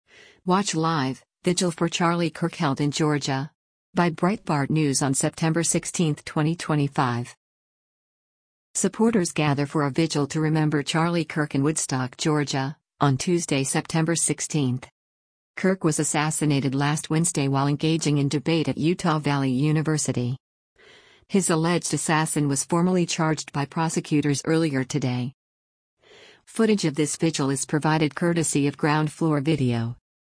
Supporters gather for a vigil to remember Charlie Kirk in Woodstock, Georgia, on Tuesday, September 16.